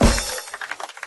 HeavenStudioPlus/Assets/Resources/Sfx/games/drummingPractice/hit.ogg at 48c0f0994c0153d0fdc865bd28056f774c5151e1
hit.ogg